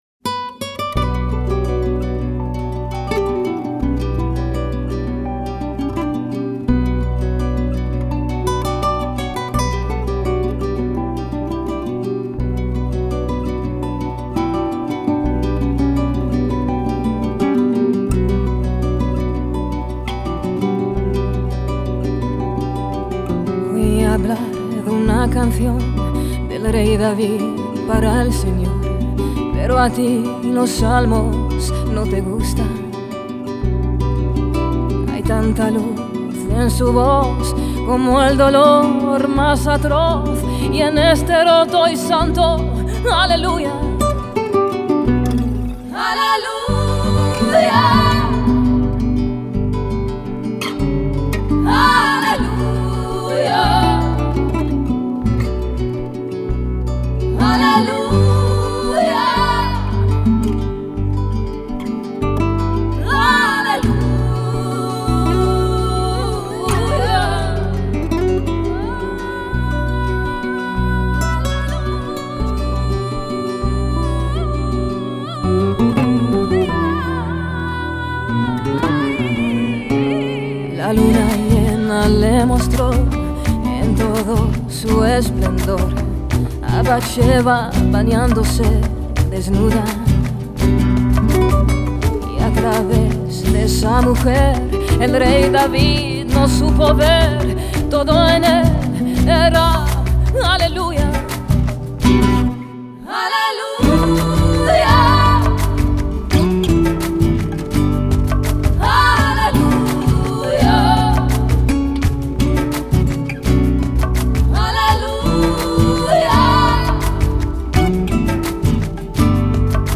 Genre: Ladino